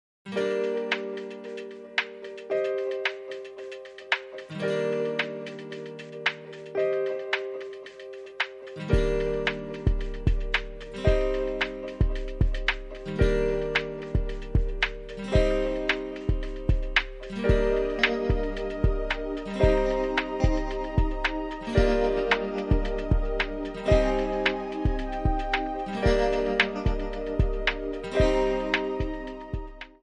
Buy With Backing Vocals.
Buy With Lead vocal (to learn the song).